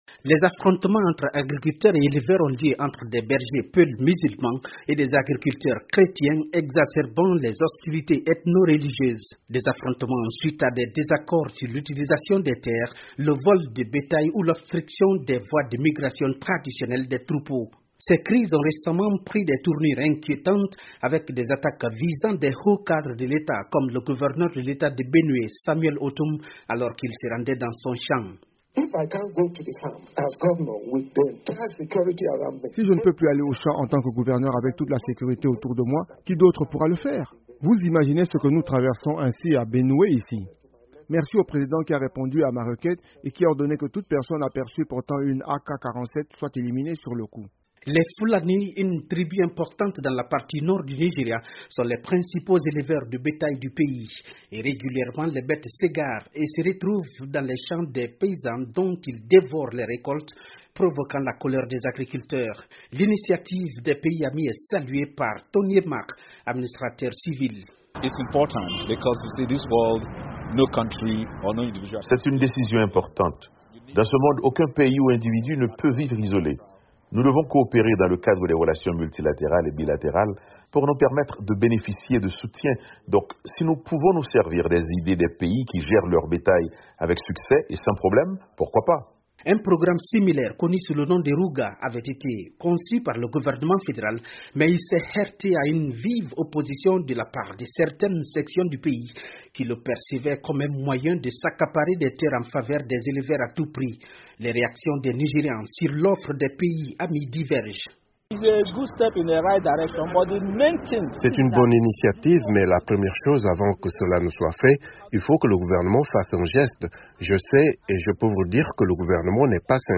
Une solution semble en vue : la Zam-bie, la Gambie et l'Afrique du Sud proposent au Nigéria des cours de recyclage sur la gestion du bétail pour aider à trouver une solution durable à la crise. Qu’en pensent les Nigérians ? Réponses dans ce reportage